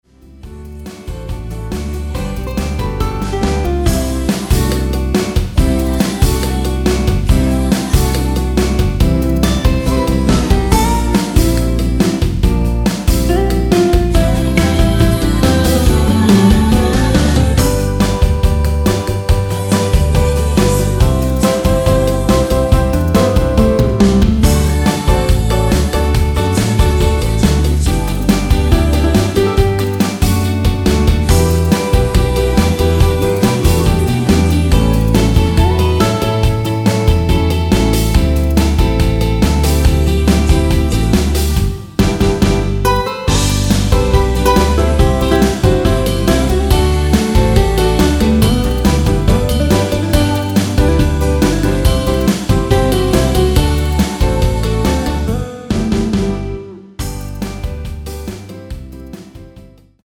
원키에서(-1)내린 코러스 포함된 MR입니다.(미리듣기 참조)
앞부분30초, 뒷부분30초씩 편집해서 올려 드리고 있습니다.
중간에 음이 끈어지고 다시 나오는 이유는